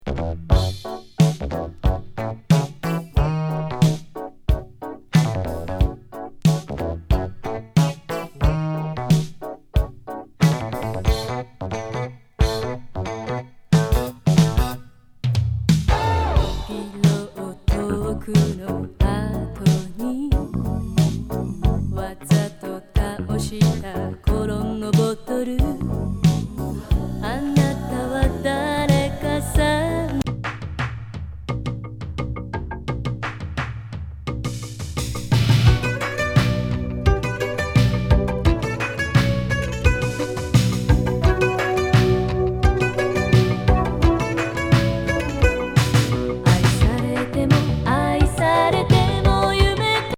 極上シンセ・エレクトロニクス・メロウ・グルーブ